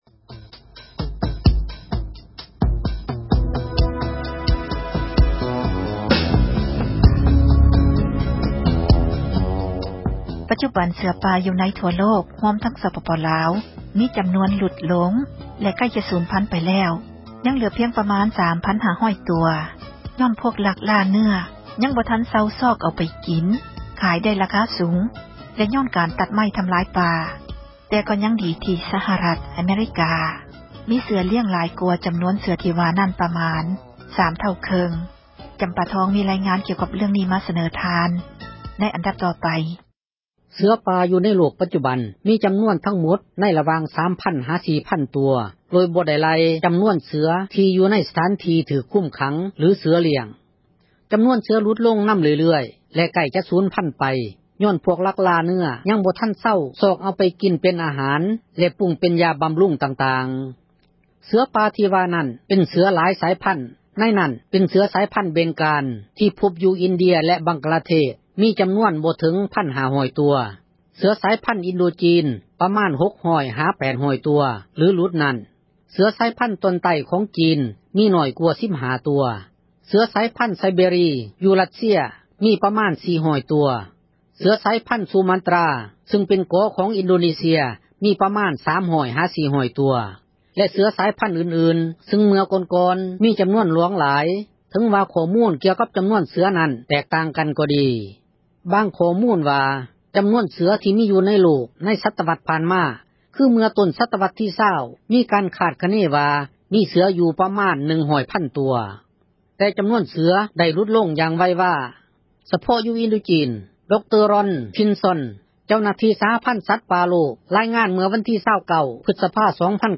ເສືອປ່າໃກ້ຈະສູນພັນ — ຂ່າວລາວ ວິທຍຸເອເຊັຽເສຣີ ພາສາລາວ